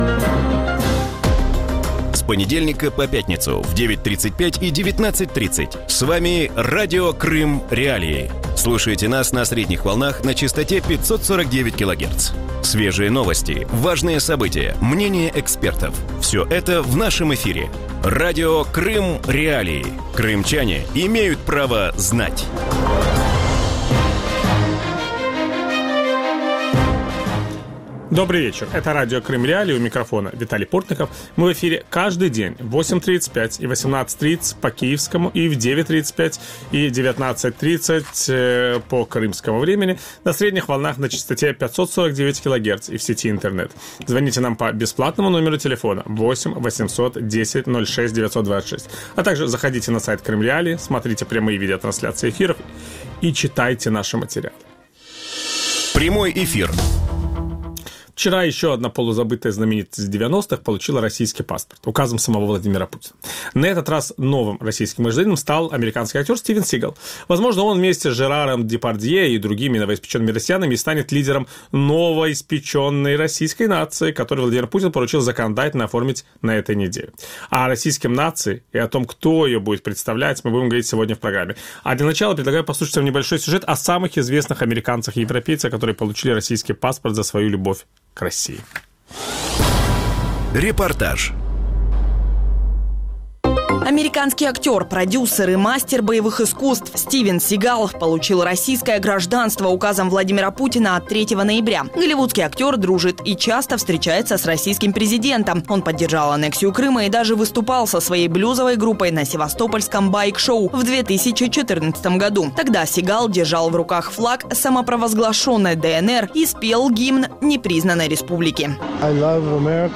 Ведучий: Віталій Портников.